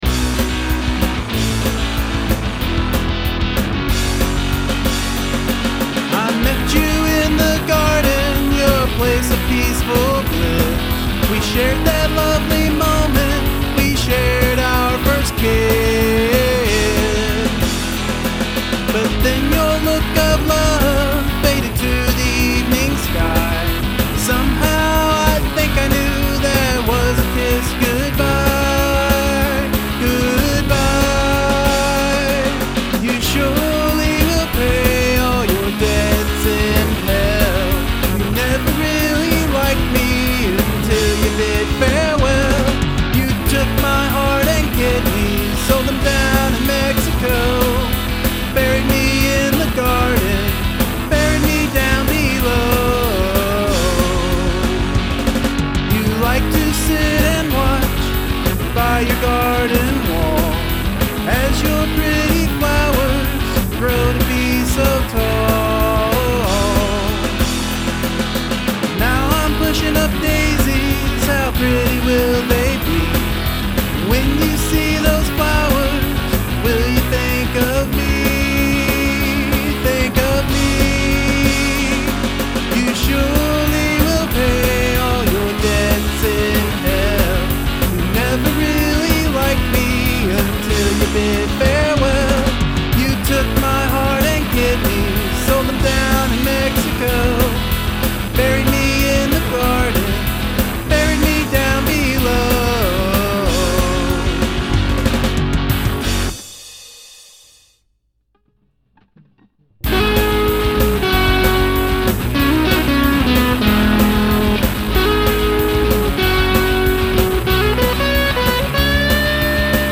I originally recorded this song at around 145 bpm but increased it to 180+ and it really makes a difference.
It's dark and yet sounds happy...love it!
The high tempo really works.
wicked! got a dead milkmen feel.
Nice pop punk tune here, great lyrics and it's the right speed!
Good sound very contemporary  great vocals